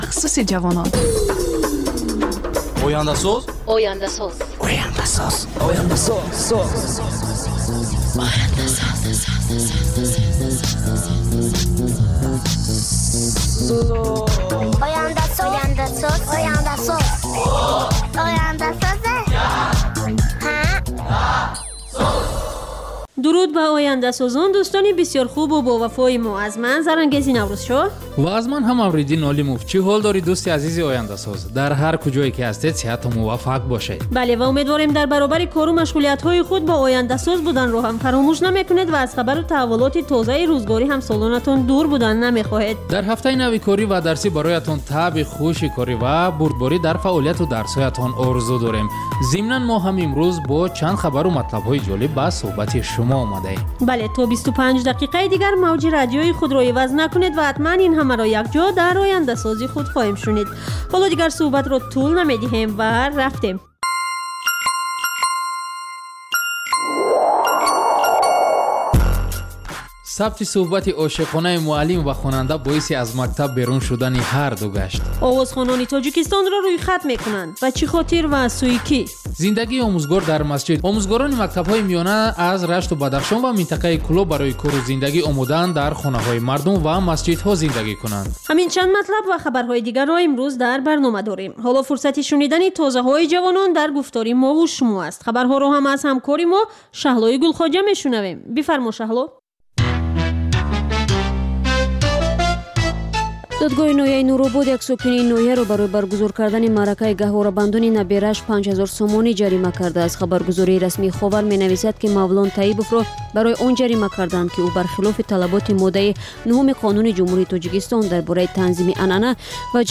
Гуфтори вижаи Радиои Озодӣ аз ҳаёти ҷавонони Тоҷикистон ва хориҷ аз он